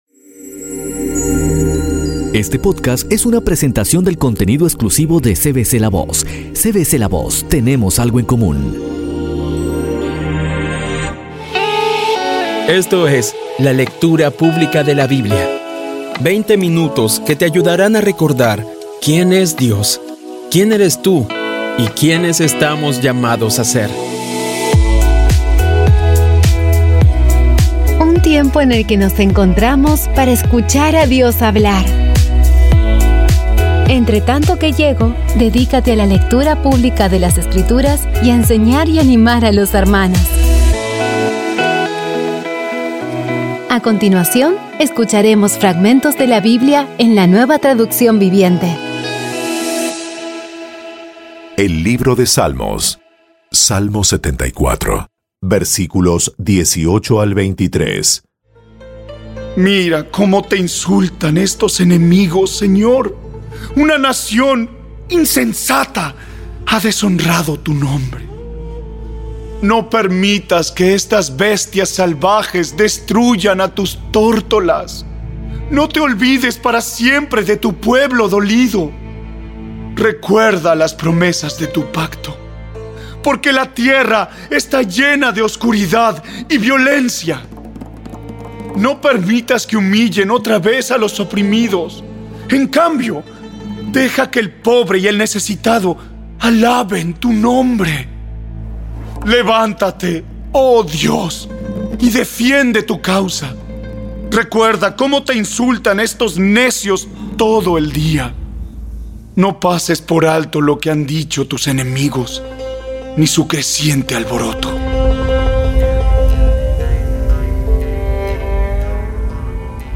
Audio Biblia Dramatizada Episodio 174